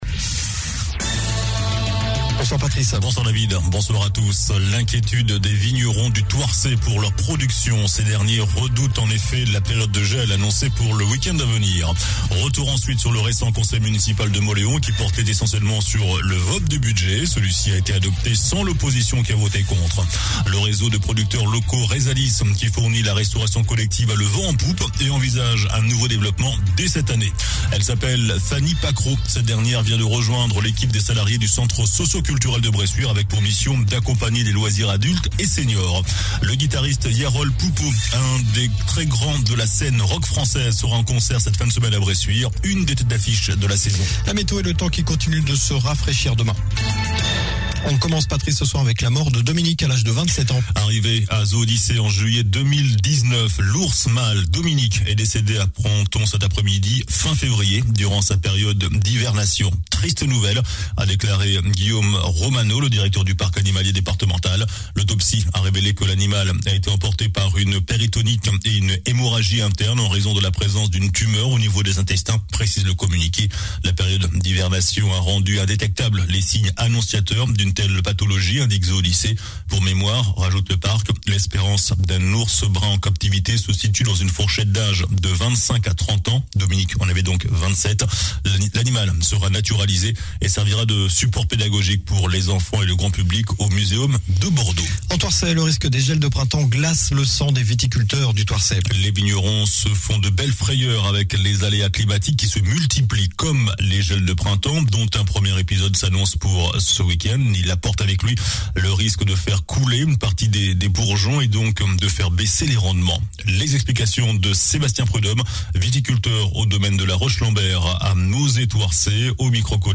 JOURNAL DU MERCREDI 30 MARS ( SOIR )